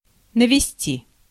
Ääntäminen
US : IPA : [eɪm]